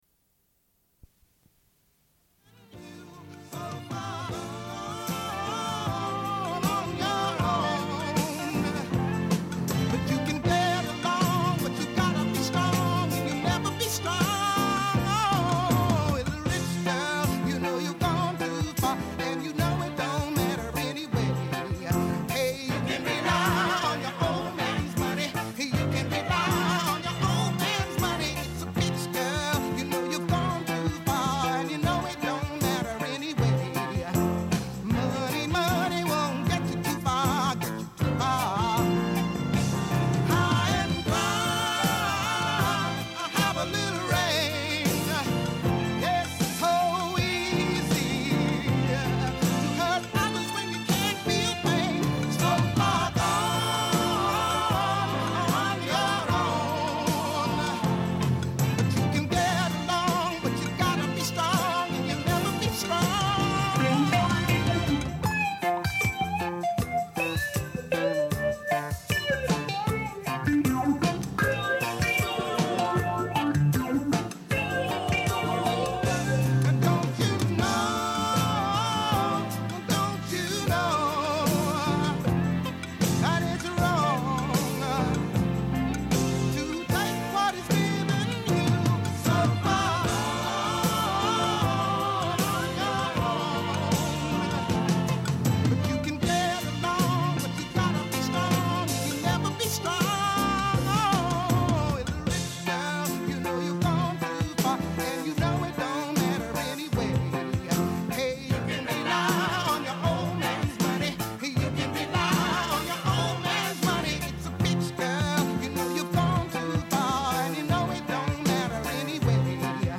Une cassette audio, face A00:31:22